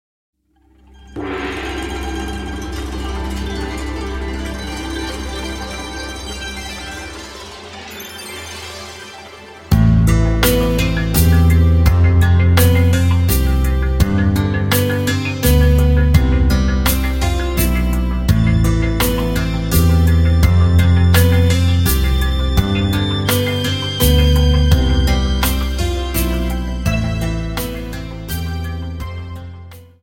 Dance: Slow Waltz 29 Song